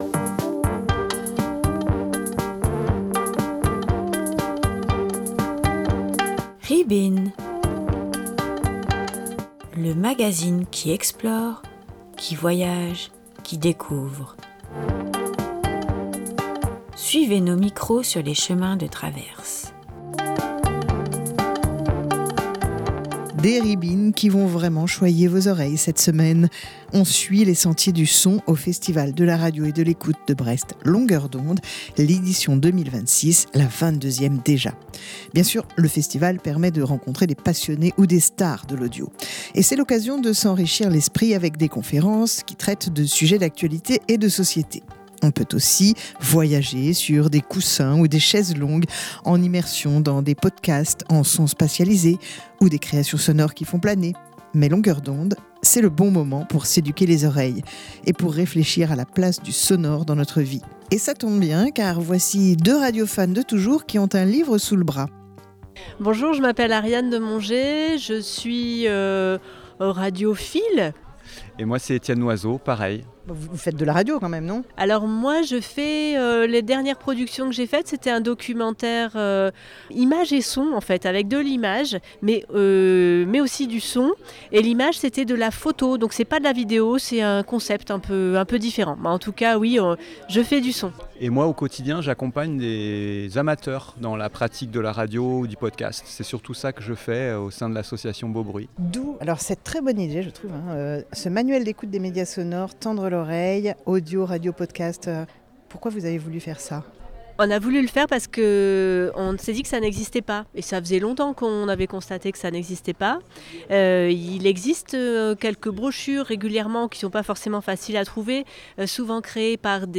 La preuve par les trois rencontres que nous avons faites lors de la 22ᵉ édition du festival, en 2026. Au programme : un manuel pour apprendre à écouter, une approche radioactive du son et une réflexion sur le vrai et le faux dans les documentaires sonores.